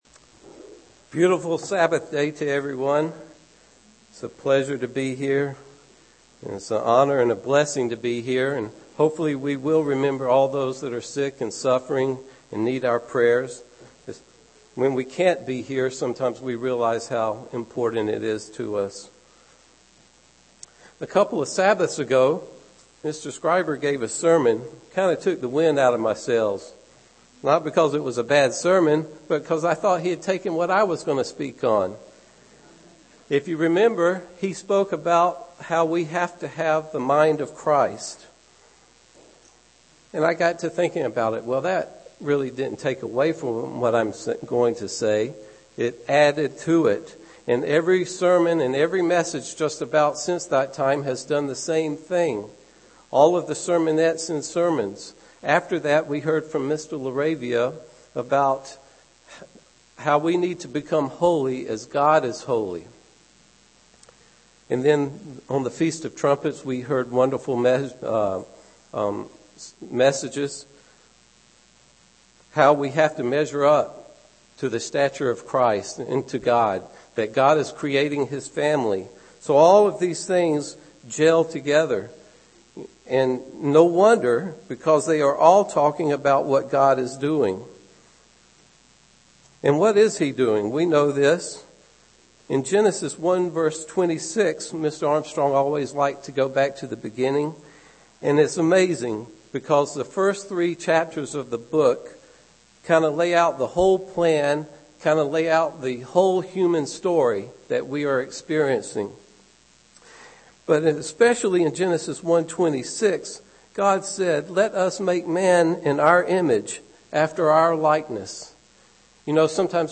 UCG Sermon Studying the bible?
Given in Phoenix East, AZ